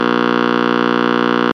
RADIO BUZZ.MP3